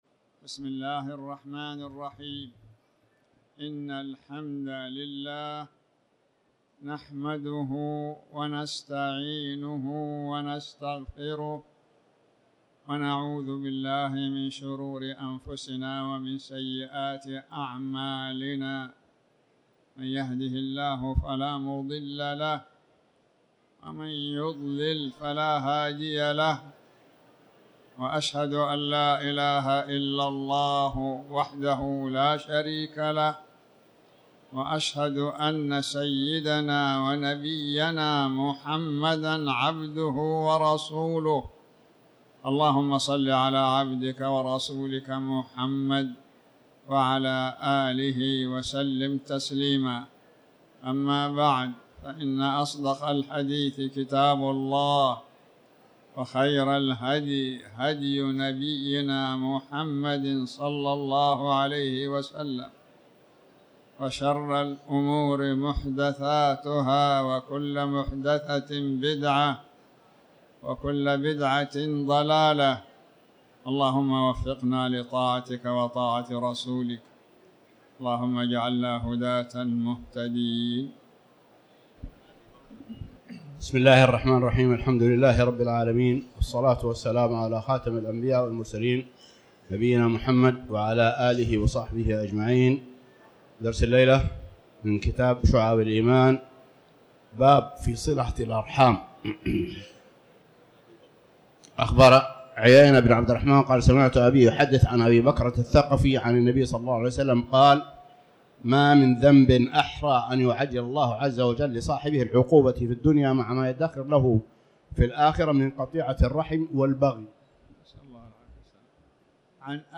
تاريخ النشر ٢٣ جمادى الأولى ١٤٤٠ هـ المكان: المسجد الحرام الشيخ